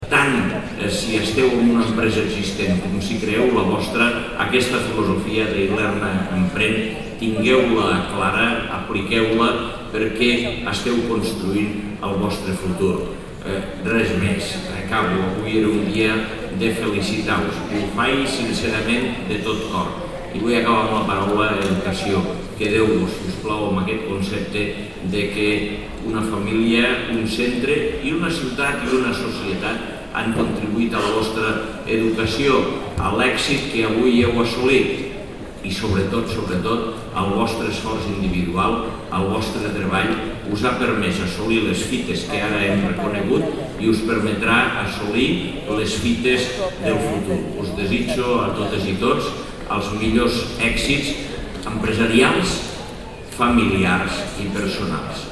tall-de-veu-de-lalcalde-angel-ros-sobre-lacte-de-lliurament-del-es-orles-academiques-dilerna